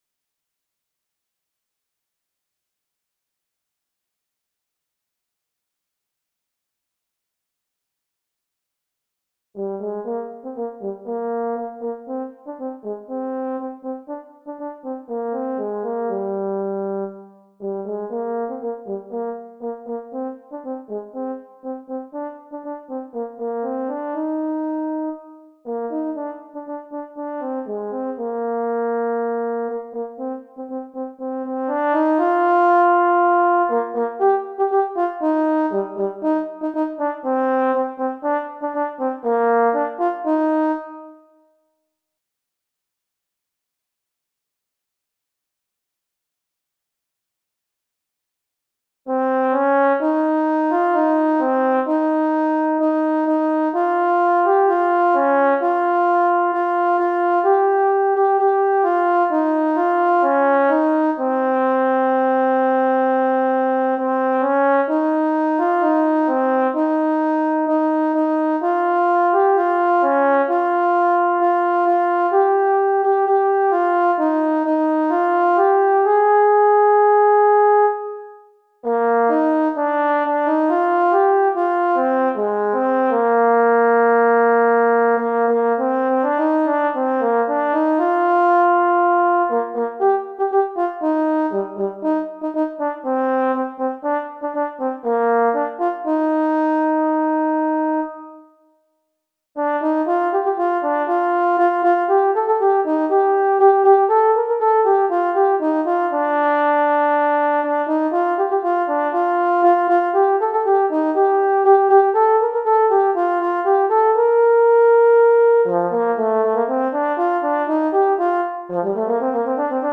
Christian, Gospel, Sacred.
set to a fast past, energetic jig